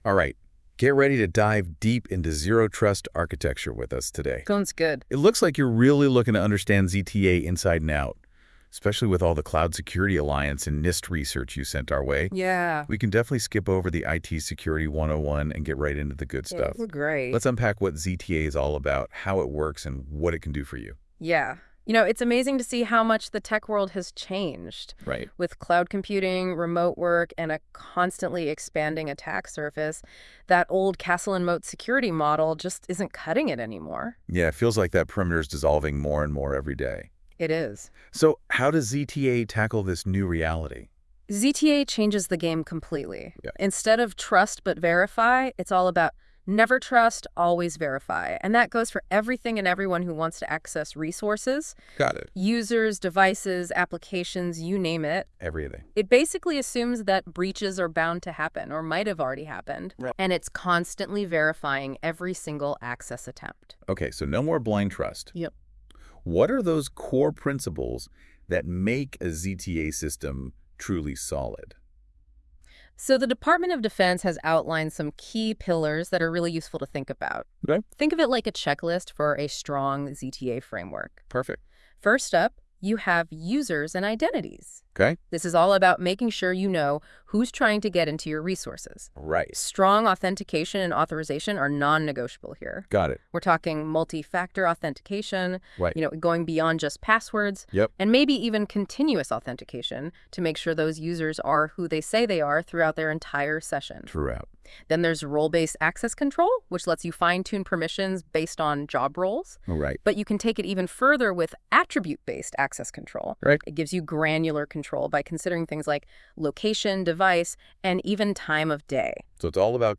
Here is an AI generated podcast on Unit 1 (Introduction to Zero Trust Architecture).